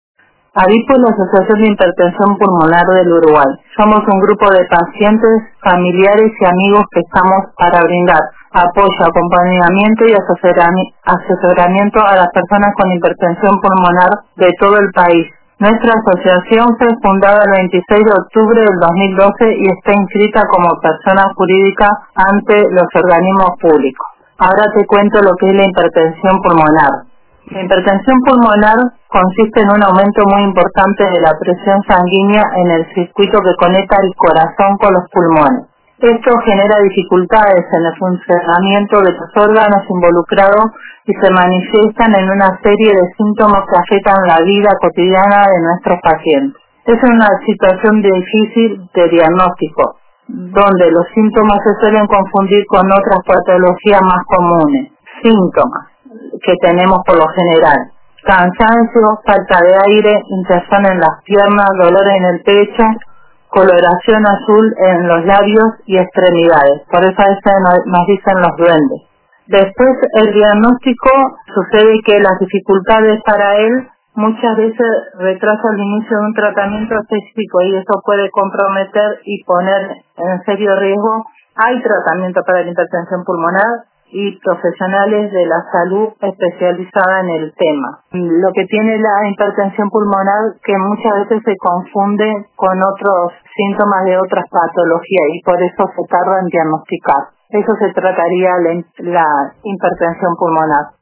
En charla